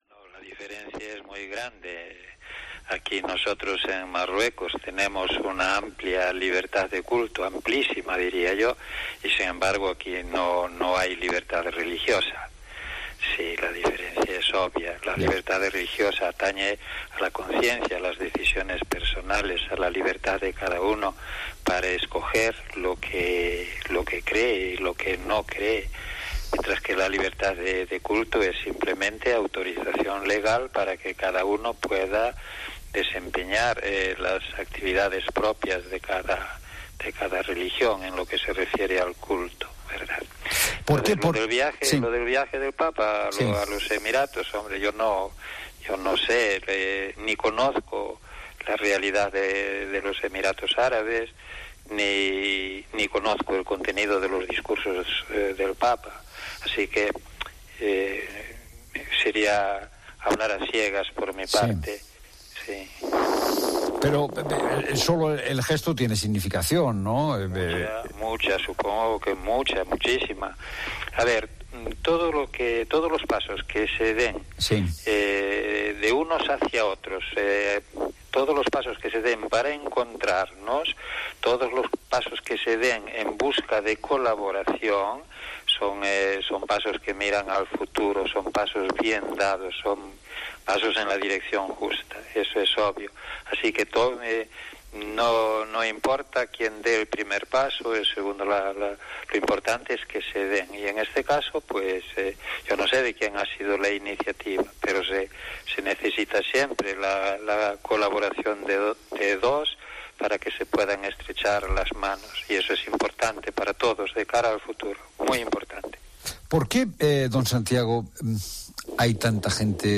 Santiago Agrelo Martínez, Arzobispo de Tánger ha hablado en La Tarde de COPE sobre la situación que viven las cientas de personas que cruzan cada día El Estrecho. Además, ha hablado sobre la política a nivel mundial y como estos no están actuando en consecuencia .